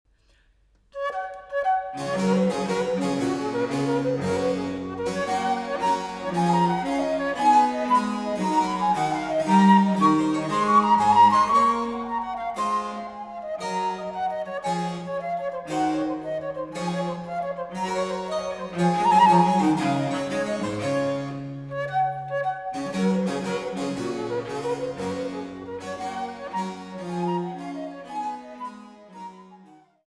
Sarabande